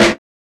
Snares